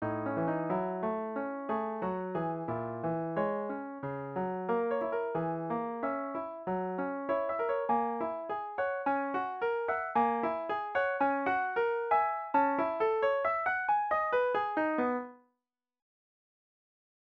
また音源②はそこまで速くしていませんが、早めに速くしすぎて後半の効果が薄くなっているように感じます。